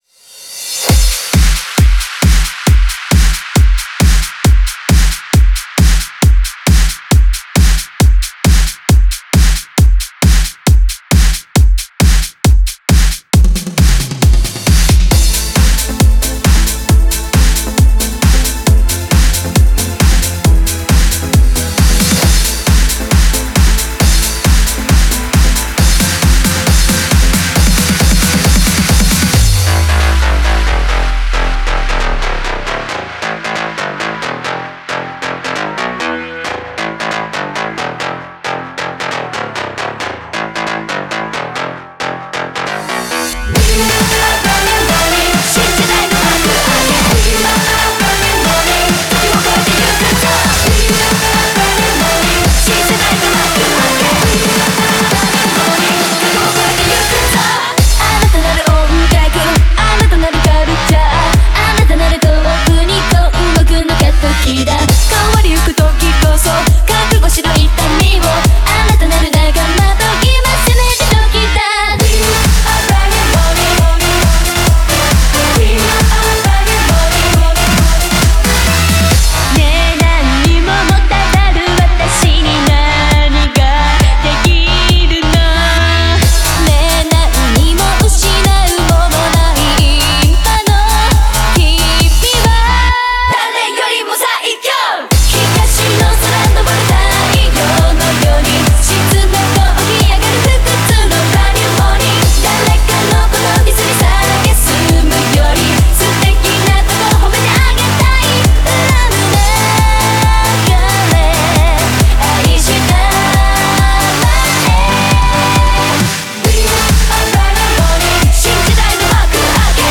Genre : House / Electro
BPM : 135 BPM
Release Type : Bootleg